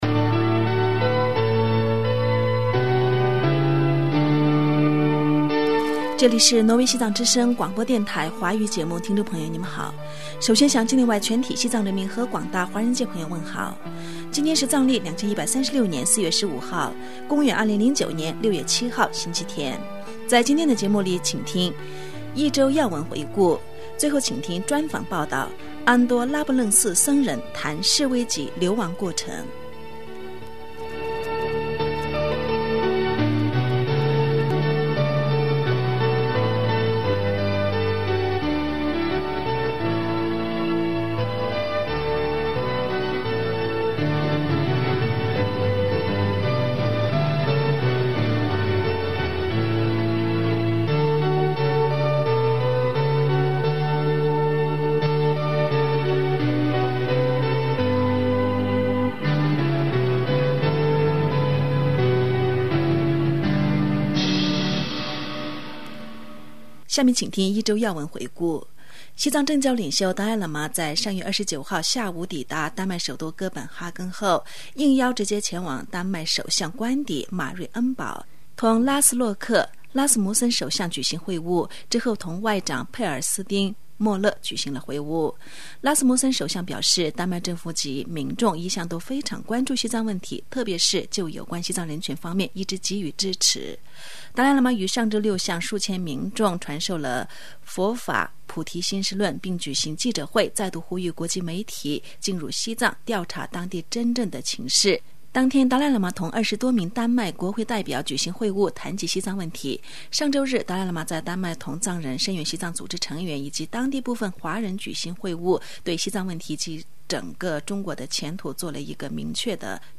专访